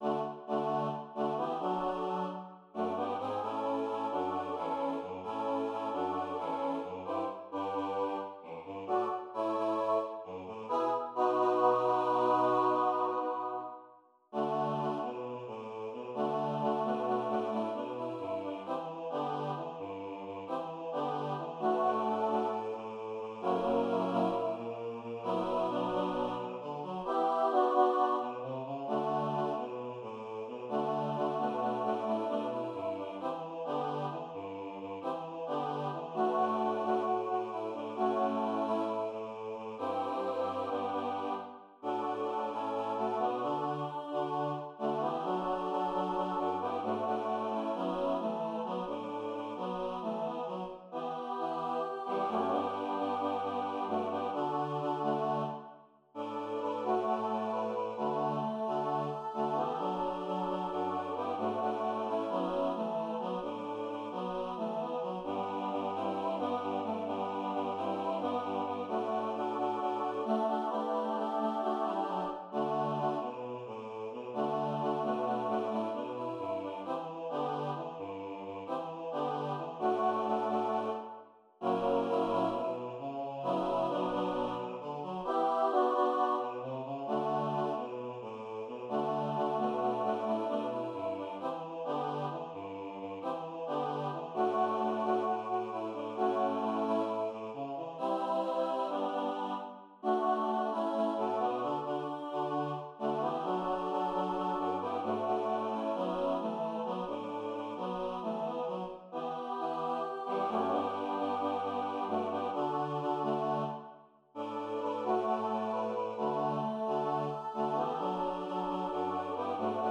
kan synges både a cappella og med akkompagnement